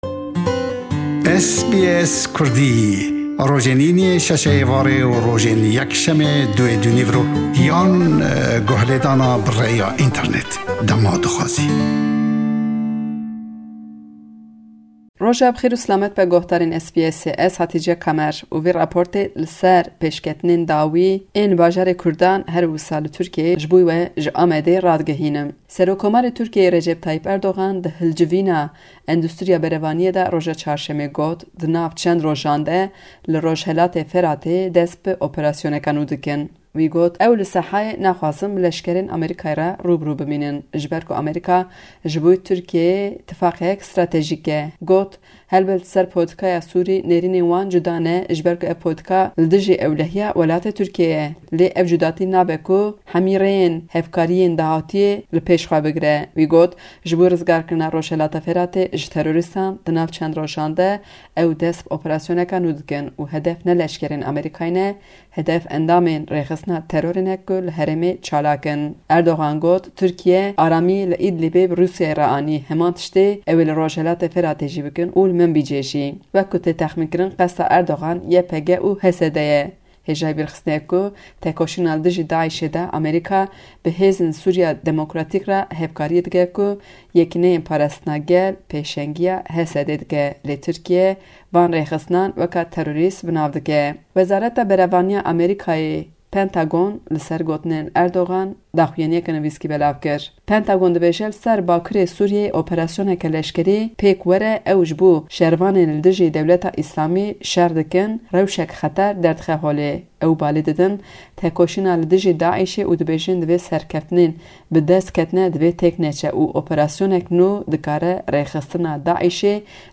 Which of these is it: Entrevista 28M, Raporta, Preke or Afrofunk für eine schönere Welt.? Raporta